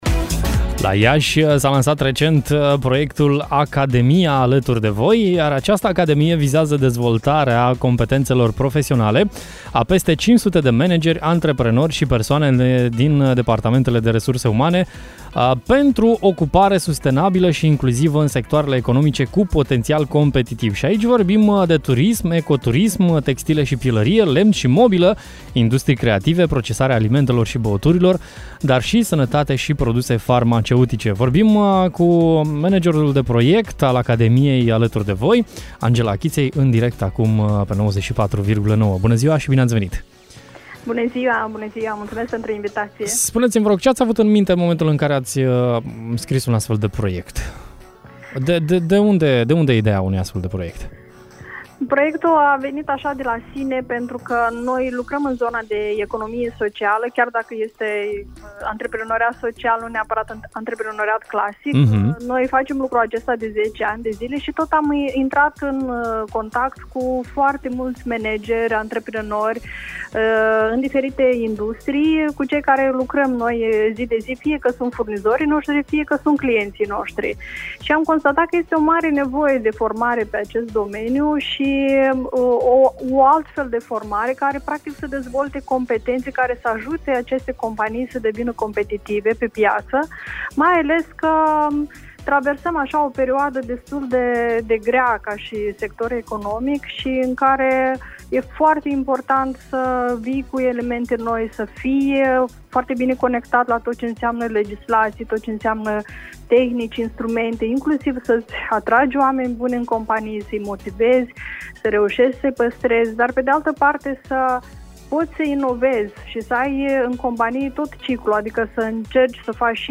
Mai multe detalii despre proiectul dedicat managerilor și antreprenorilor am aflat în direct la Radio Hit